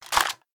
255081e1ee Divergent / mods / Soundscape Overhaul / gamedata / sounds / material / large-weapon / collide / bounce01hl.ogg 9.3 KiB (Stored with Git LFS) Raw History
bounce01hl.ogg